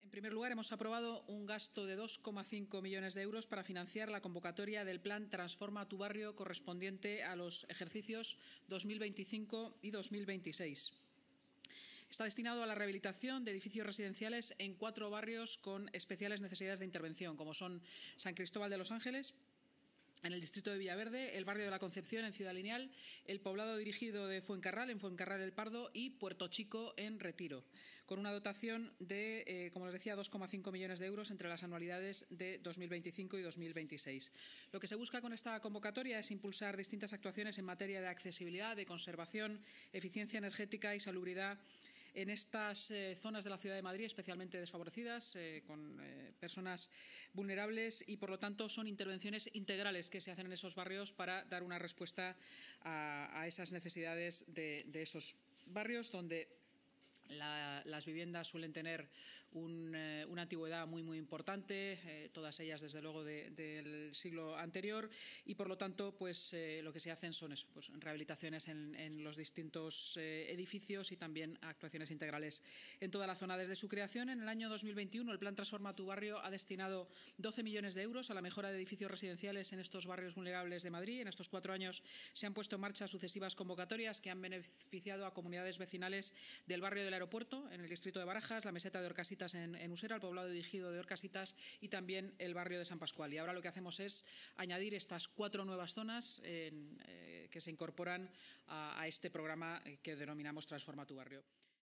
La vicealcaldesa y alcaldesa en funciones, Inma Sanz, durante la rueda de prensa